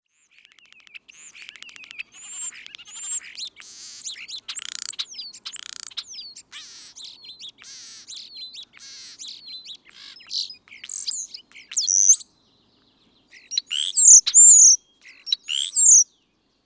На этой странице собраны разнообразные звуки скворцов: от мелодичного пения до характерного свиста.
Песня скворцов в утренней заре